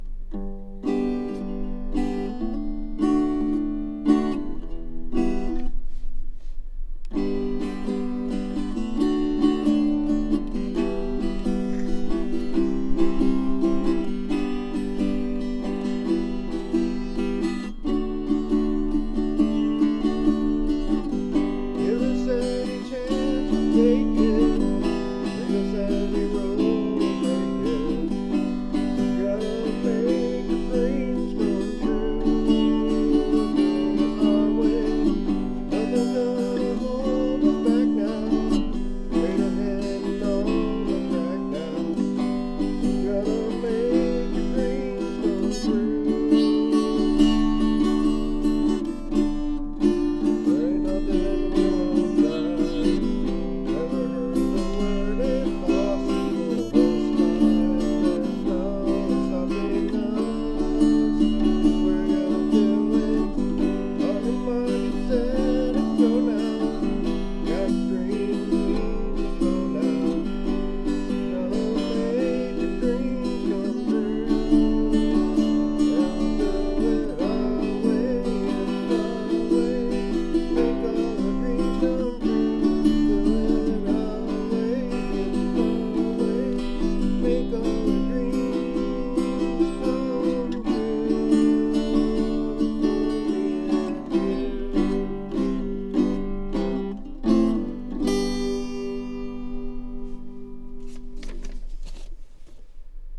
Guitar: Martin Backpacker
Harmonica: Lee Oskar